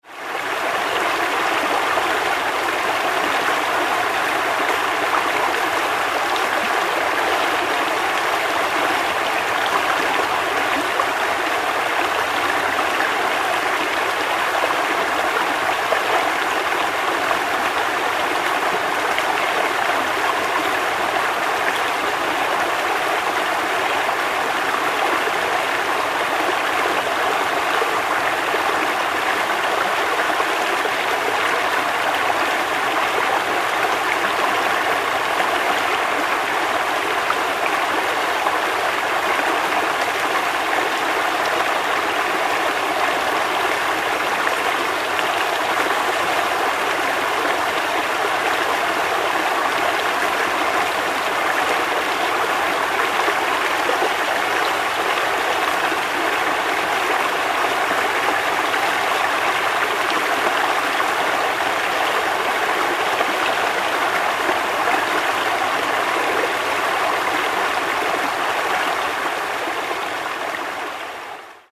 Звуки реки